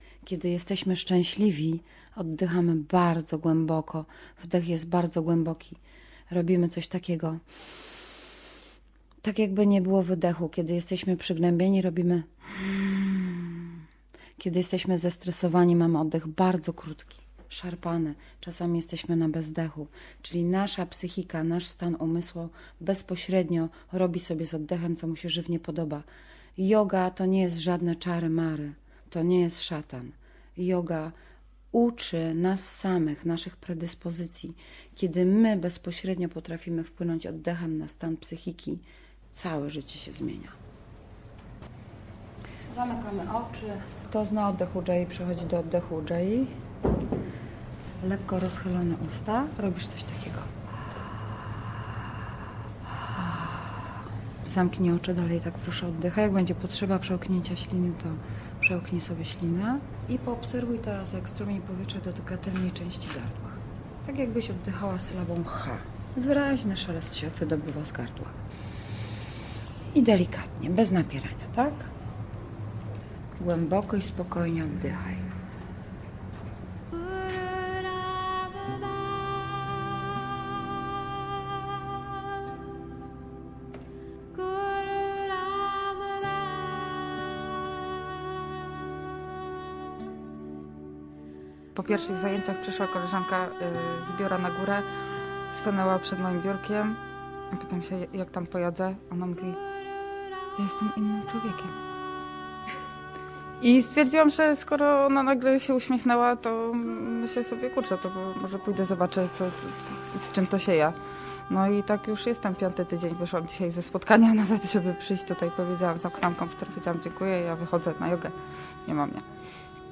Głęboki oddech - reportaż